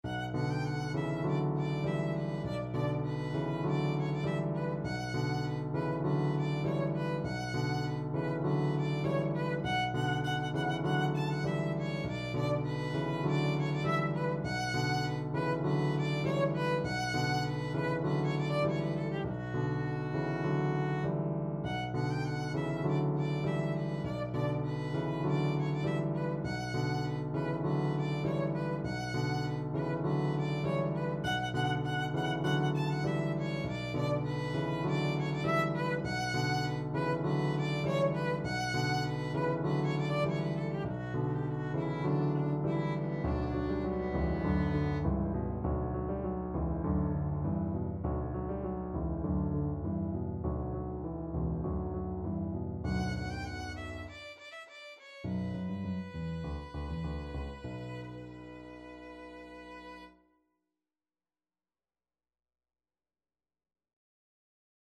4/4 (View more 4/4 Music)
Allegro (View more music marked Allegro)
Classical (View more Classical Violin Music)